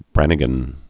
(brănĭ-gən)